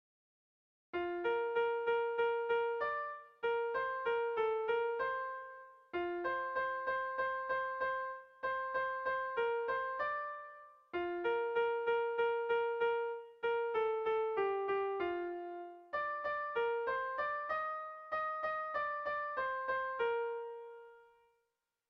Irrizkoa
Zortziko txikia (hg) / Lau puntuko txikia (ip)
ABDE